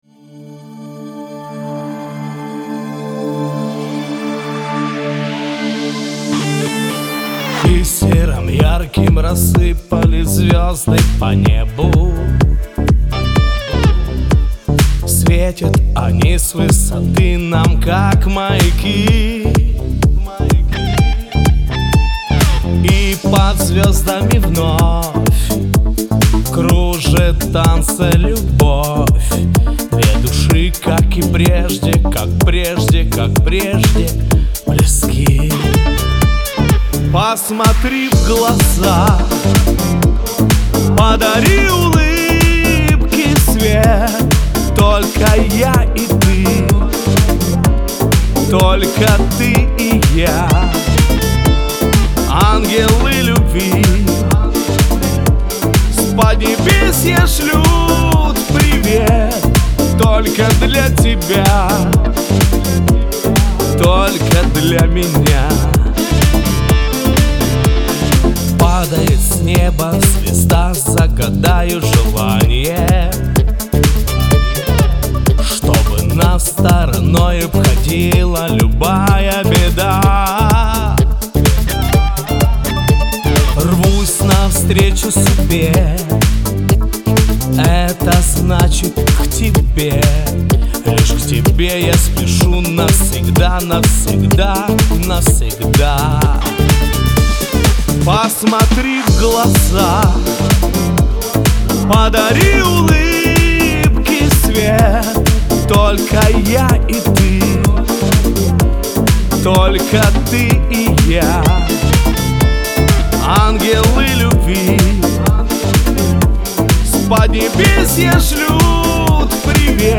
[demo] Посмoтpи в глaза (pop/русский шансон)
Прошу критики по сведению. Версия с мощным басом для ютуба/мобильников.